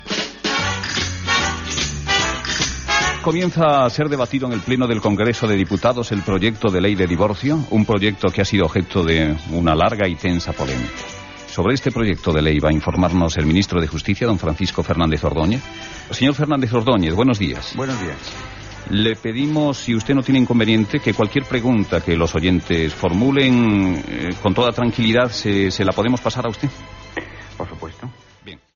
Info-entreteniment
Fragment extret del programa "Documentos" 70 años de RNE (2007)